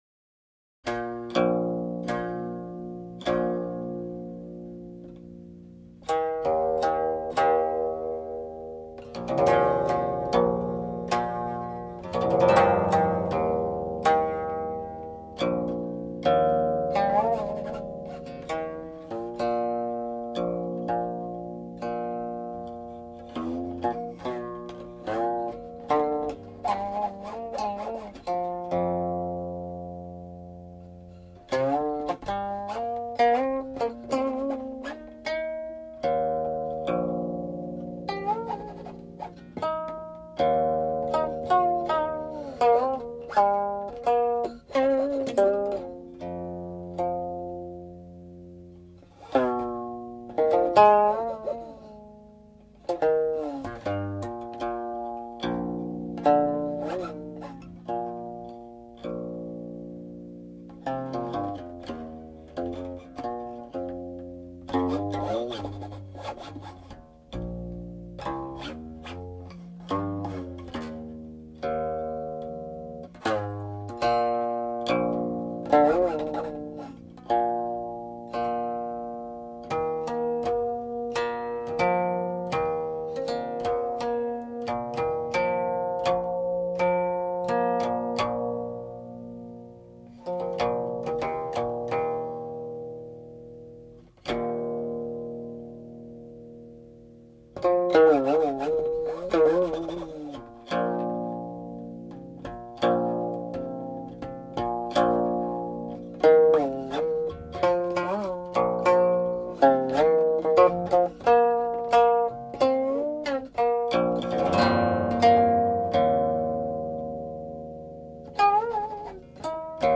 Transcription includes the modal prelude; also add the 1525 prelude Da Guan Yin?)
Nine sections (untitled; titles here are from Chongxiu Zhenchuan Qinpu)20
(05.50) -- harmonics